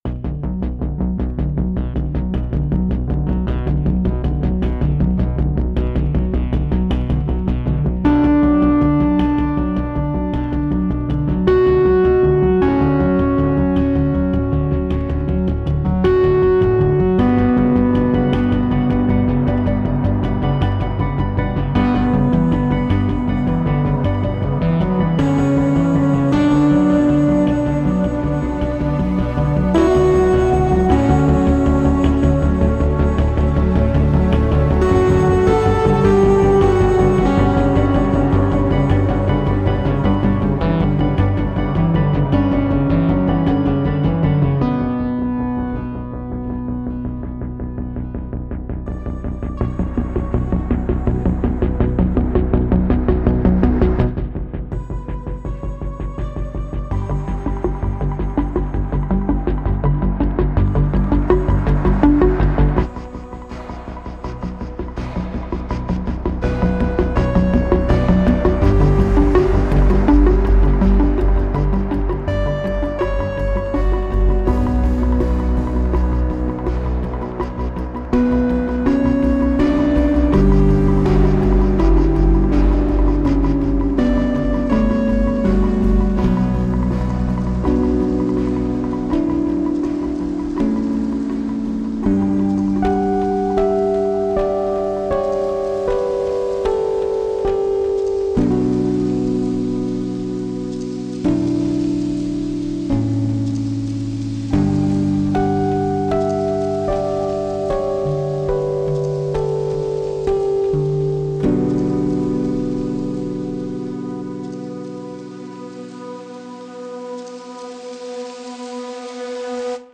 Mosaic Keys探索了电键的声音温暖，提供了移动的合成键盘，有机发现的声音和有效的声音设计元素。
设计的电键将抒情旋律叠加在温暖的电影音景上。
合成键与有机发现的声音和复杂的噪音层相结合，创造出郁郁葱葱的现代纹理。
Mosaic Keys拥有180多个手工快照，将电键重新设想为一种复杂的评分工具，使作曲家能够快速轻松地将抒情旋律叠加在丰富的电影音景和脉动的节奏床上。